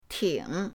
ting3.mp3